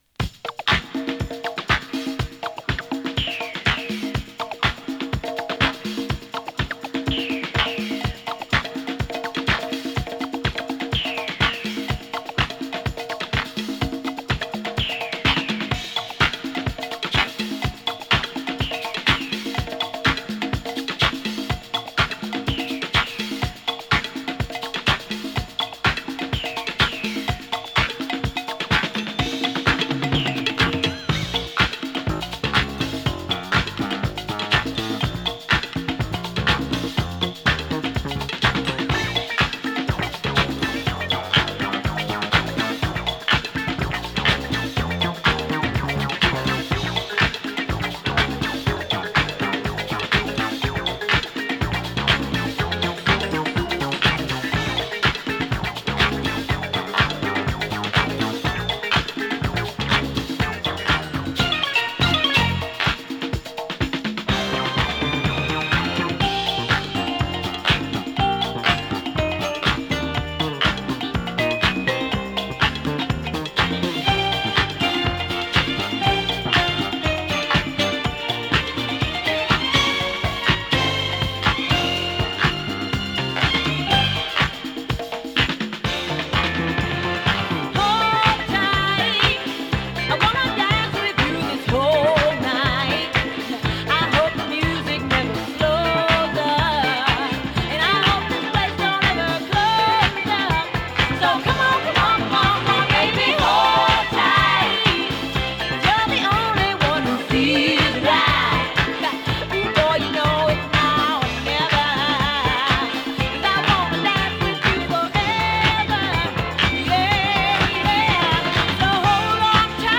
溌剌としたフィリー系ダンサーで、中盤から長いパーカス・ブレイクをフィーチャーした